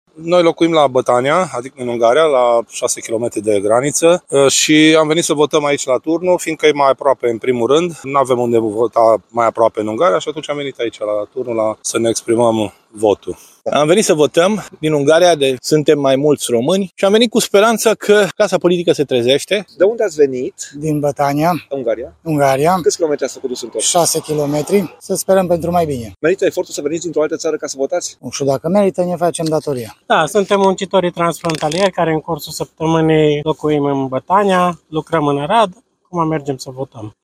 Secția de votare din localitatea Turnu, județul Arad, este una dintre cele mai apropiate de granița cu Ungaria, așa că mai mulți români care locuiesc în țara vecină au venit deja să și exprime opțiunea electorală.
08-Voxuri-Ungaria.mp3